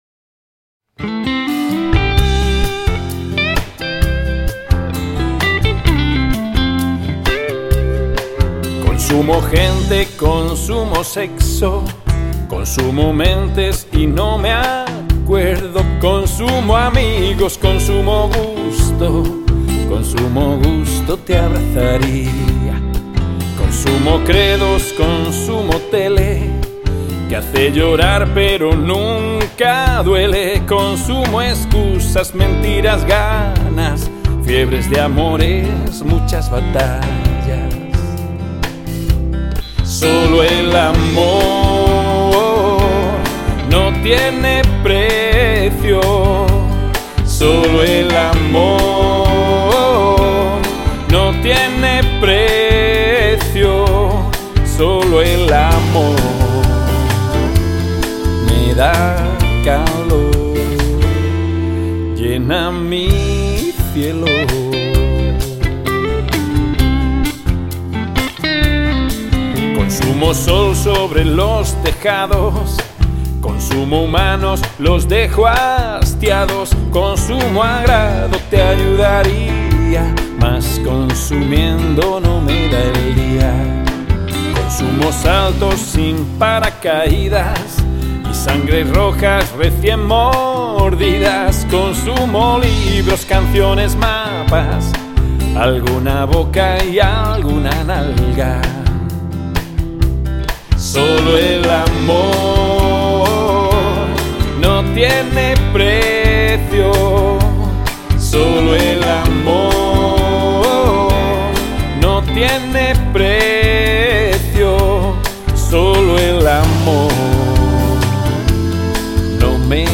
Con un objetivo claro de humanizar, esta canción se convierte en un himno que nos invita a reflexionar sobre la importancia de colocar al ser humano en el centro de todo lo que hacemos.